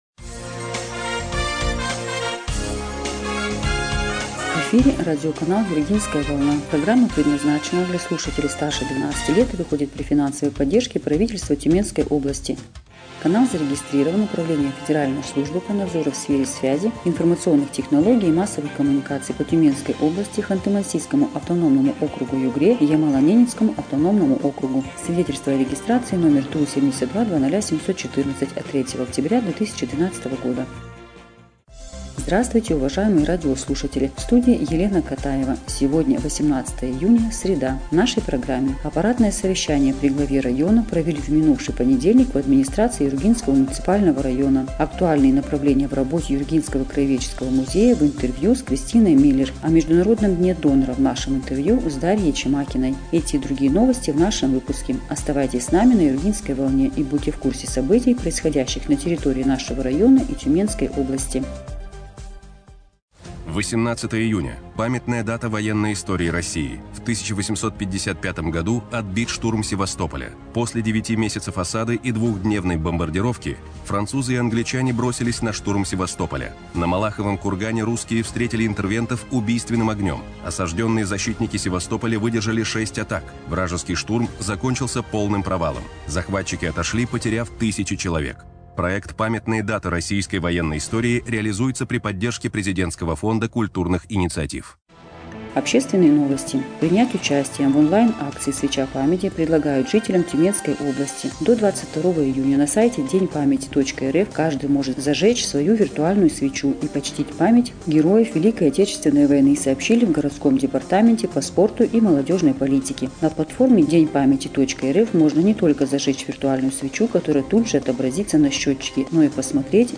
Эфир радиопрограммы "Юргинская волна" от 18 июня 2025 года